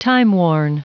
Prononciation du mot timeworn en anglais (fichier audio)
Prononciation du mot : timeworn